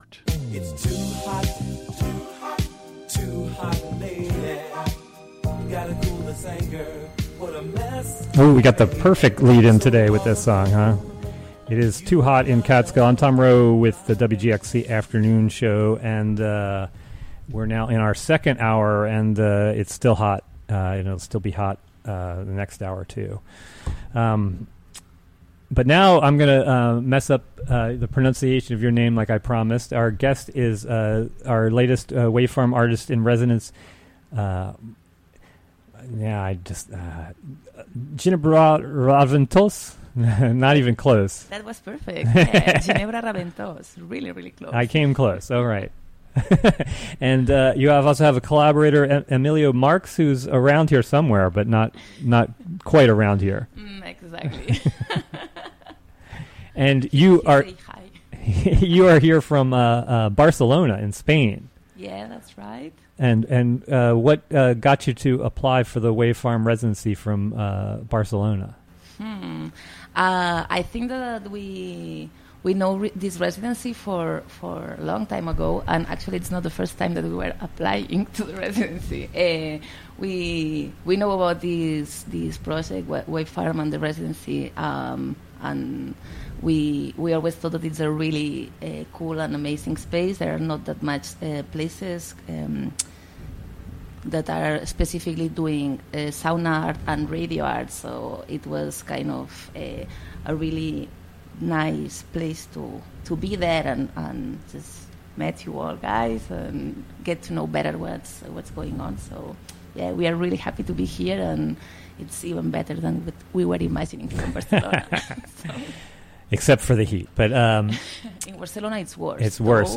joined live in the Catskill studio